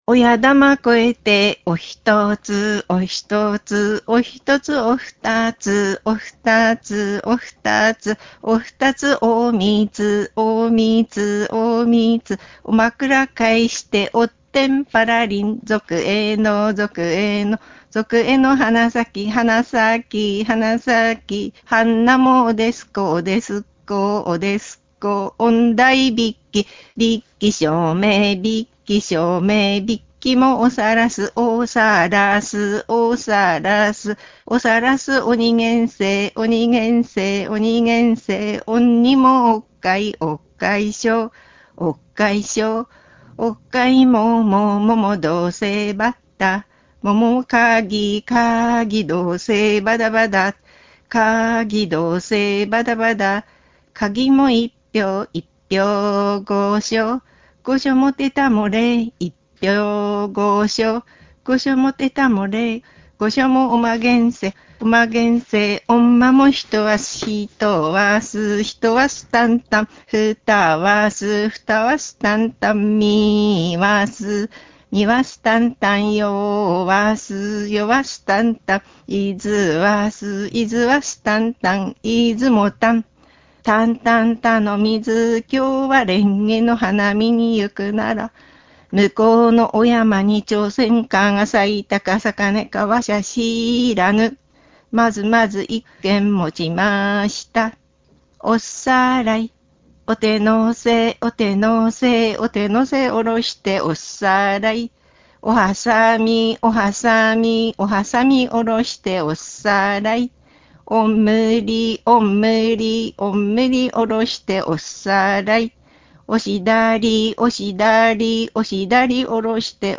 お手玉うた